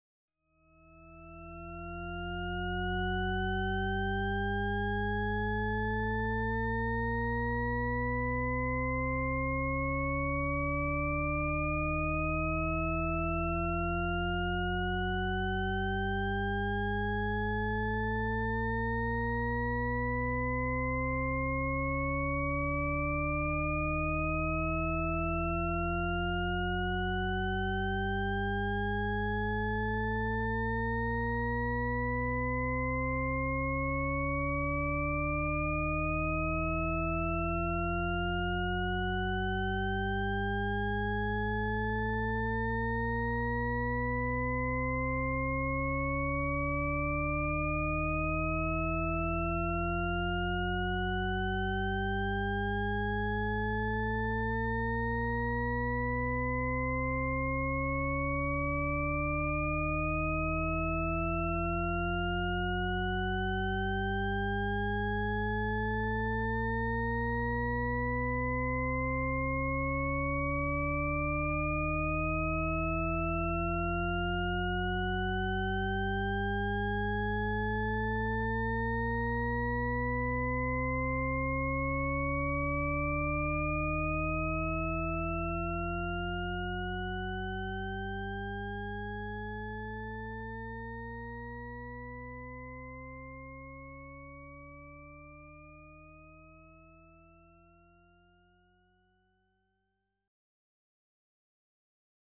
Le glissando de Risset (explication et utilisations) :
Il s’est intéressé à cette illusion et a ainsi créé une version continue de celle-ci, un glissando qui monte indéfiniment.